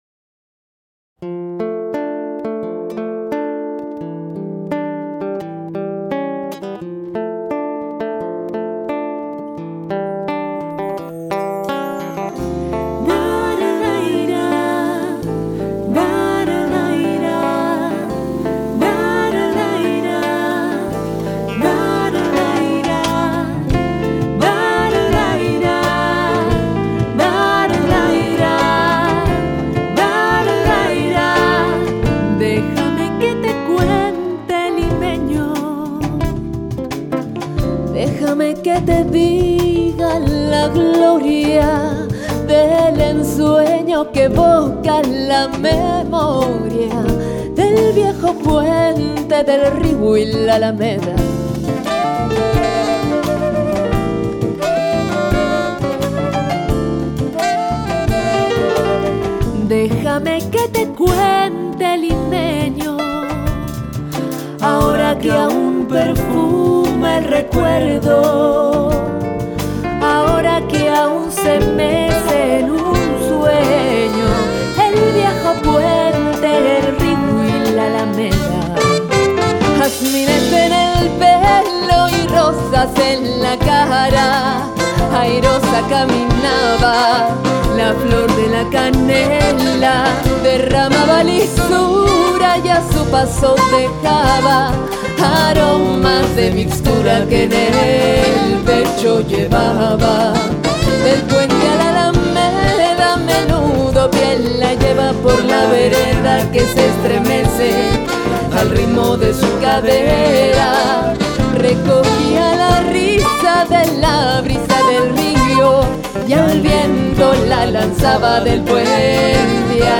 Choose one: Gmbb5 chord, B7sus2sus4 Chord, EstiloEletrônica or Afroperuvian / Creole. Afroperuvian / Creole